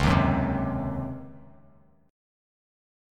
C#M7sus2sus4 chord